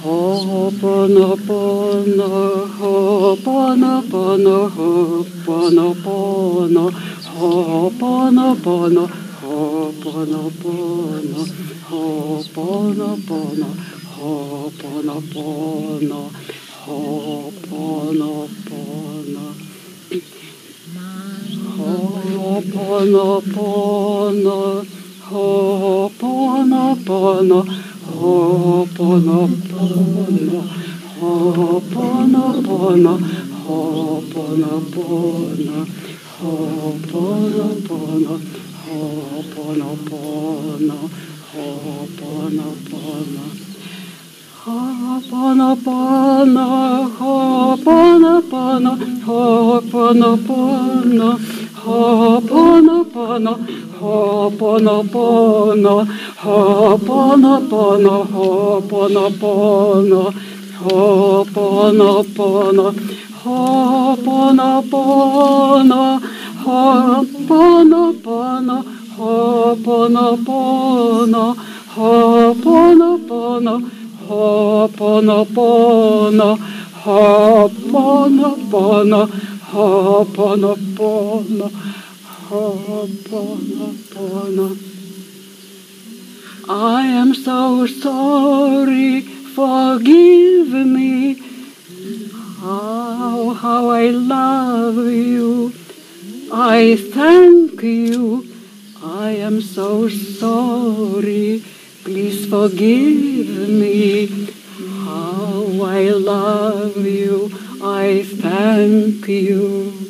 Ho’oponopono Meditation